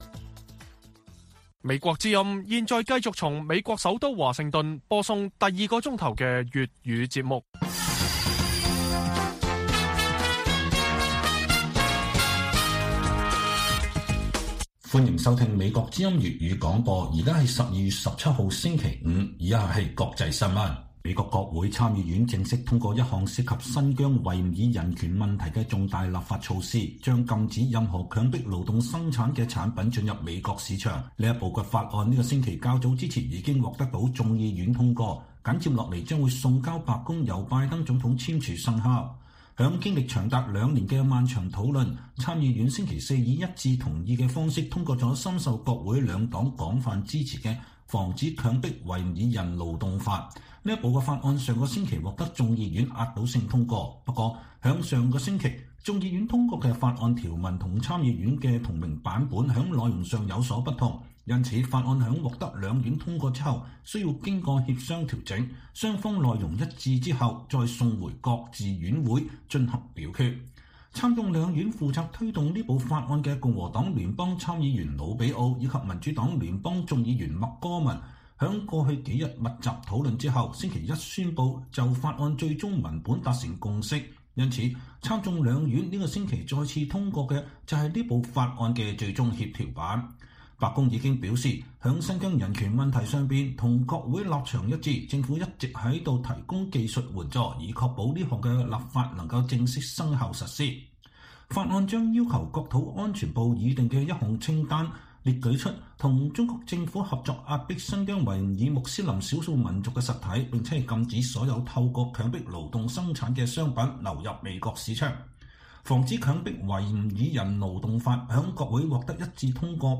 粵語新聞 晚上10-11點: 美參院通過重大涉疆人權法案 禁止強迫勞動產品進入美國